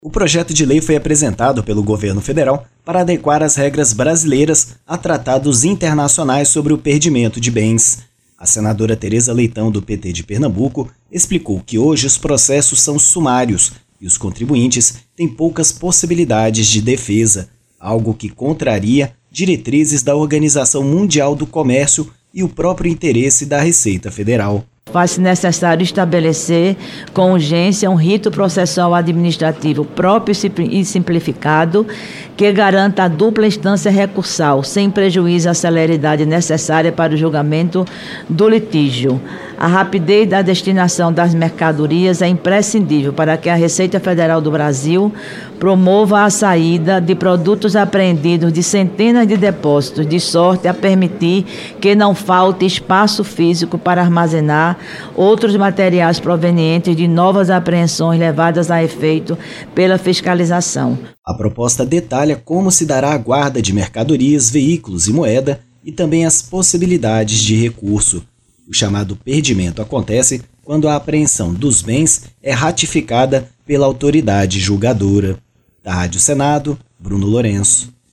A proposta, que segue em regime de urgência constitucional para o Plenário do Senado, alinha Brasil a tratados internacionais e às regras da Organização Mundial do Comércio, como explicou a relatora, Teresa Leitão (PT-PE).